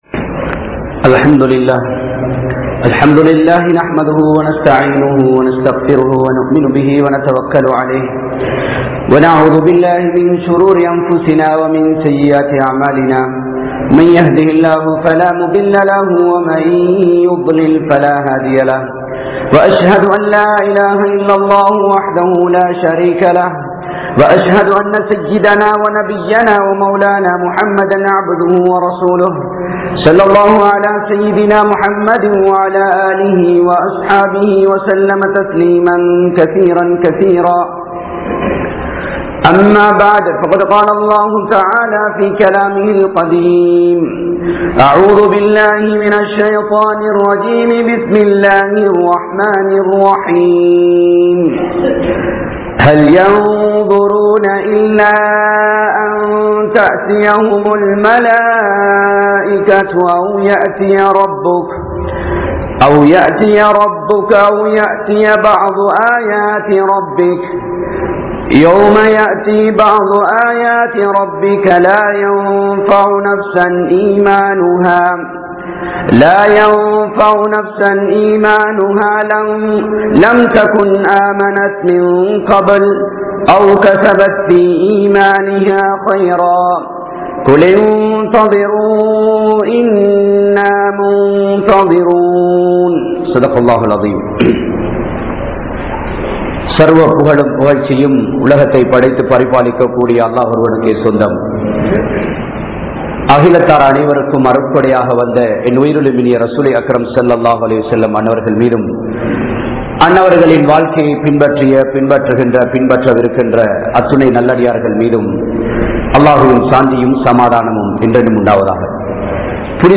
Qiyamath Naalin Adaiyaalangal(Part 07) (கியாமத் நாளின் அடையாளங்கள்) | Audio Bayans | All Ceylon Muslim Youth Community | Addalaichenai
Badhriyeen Jumua Masjith